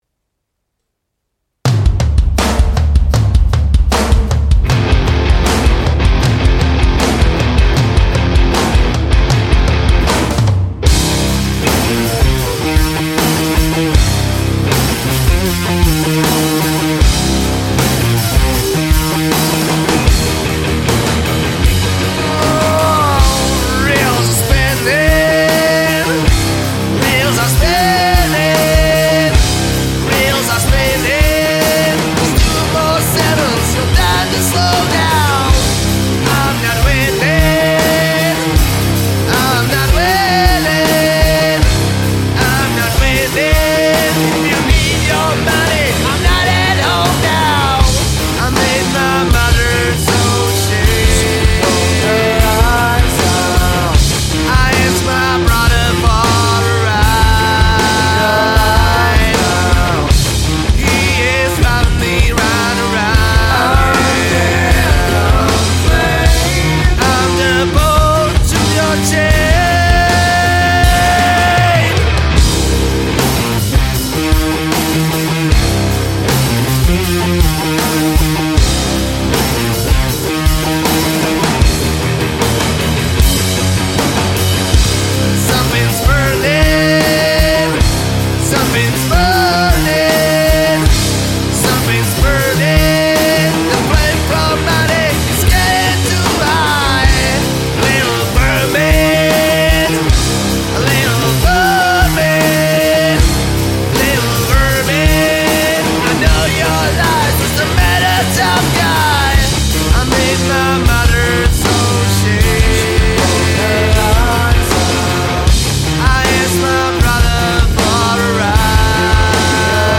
Žánr: Rock
Guitar, Vox
Drums, Vox